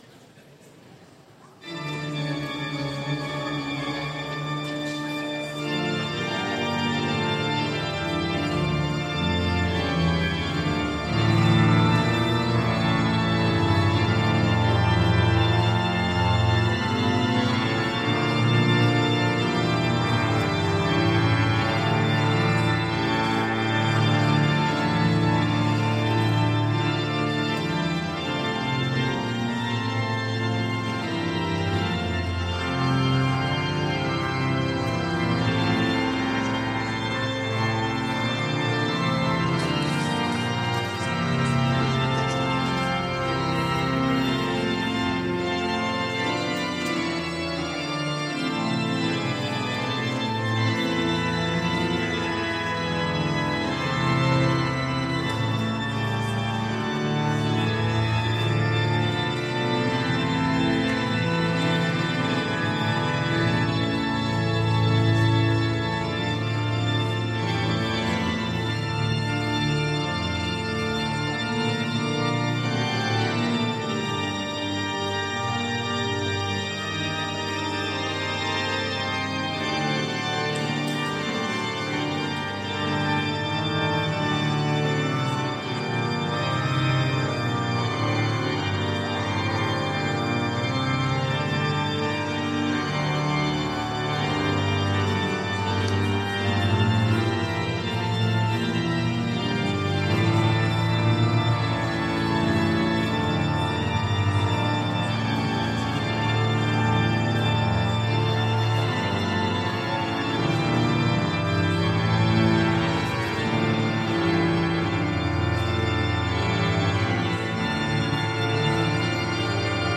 Culte des JEP 2022 - Oratoire du Louvre